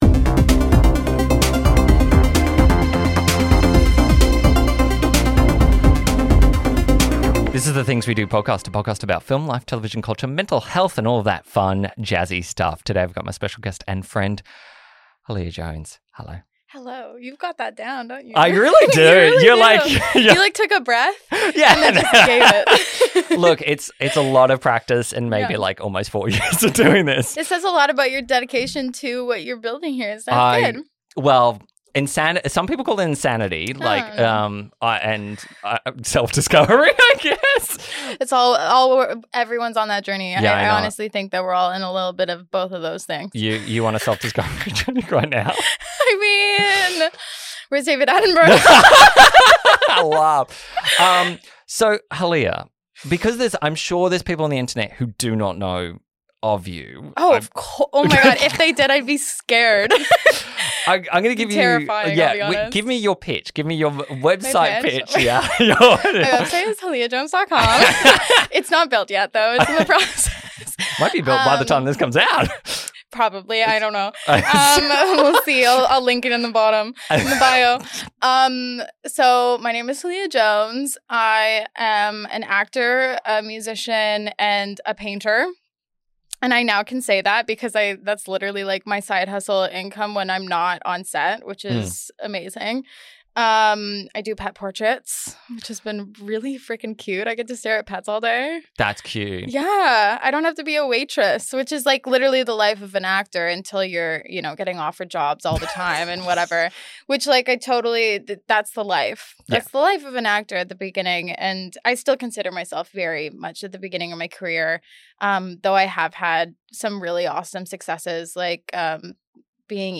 It’s an open and honest chat that’s as inspiring as it is relatable.